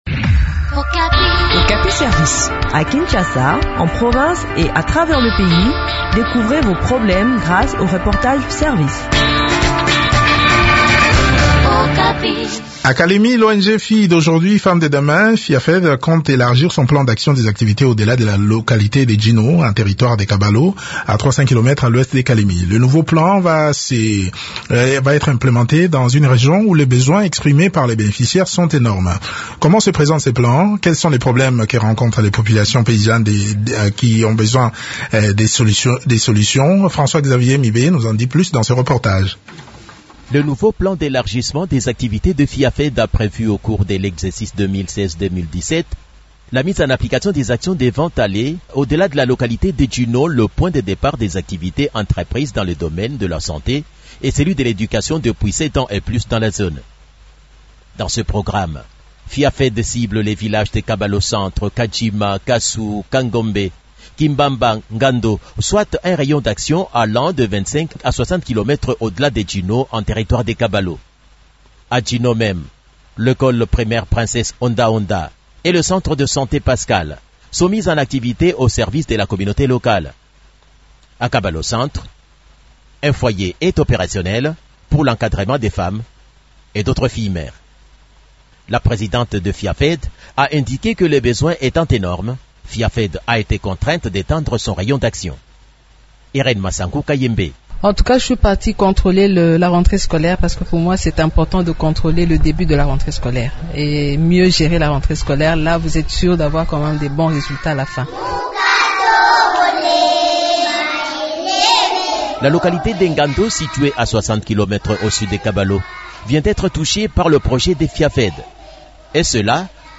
Des précisions sur les activités de FIAFED au Tanganyika dans cet entretien